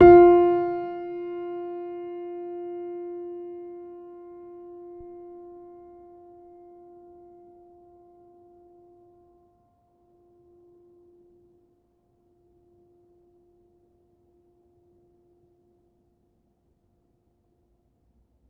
healing-soundscapes/Sound Banks/HSS_OP_Pack/Upright Piano/Player_dyn3_rr1_022.wav at main